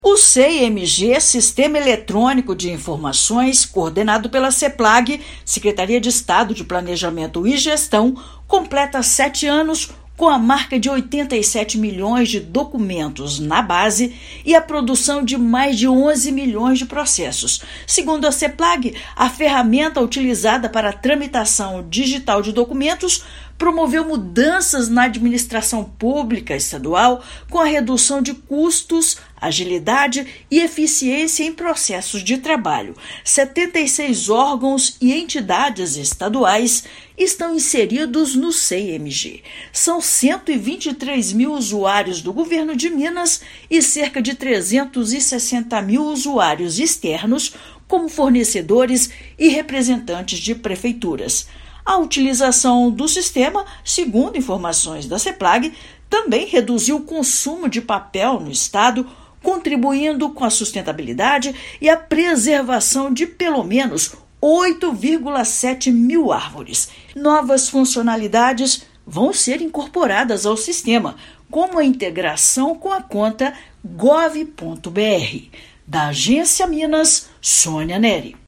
[RÁDIO] Sei!MG completa sete anos de implantação no Governo de Minas, garantindo eficiência, agilidade e redução de custos
Secretaria de Planejamento e Gestão promoveu encontro de administradores do sistema para celebrar a data e apresentar novas ferramentas. Ouça matéria de rádio.